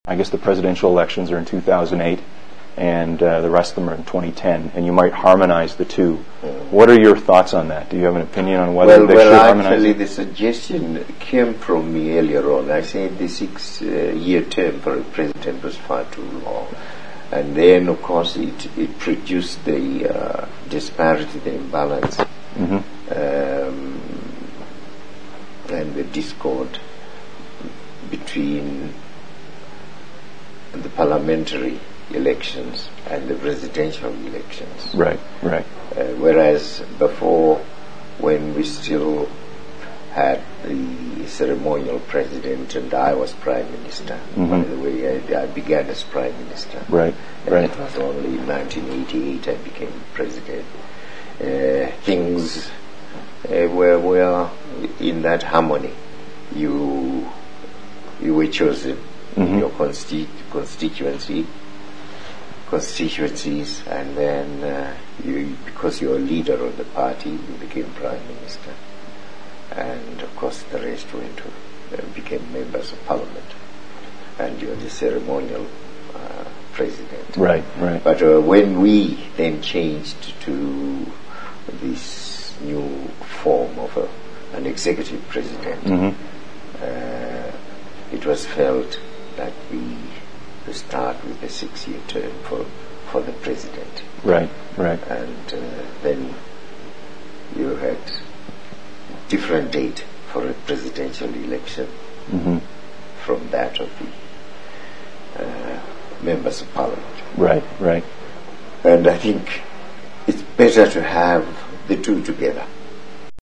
Part of the audio interview, which Mugabe gave OMNI, a Canadian television station last year and which was not broadcast due to time limitations, was made available to The Zimbabwe Guardian. In the interview President Mugabe suggests that he decided, not Zanu PF, that elections be married without making any consultations - seemingly for his own ends.
• OMNI interview extract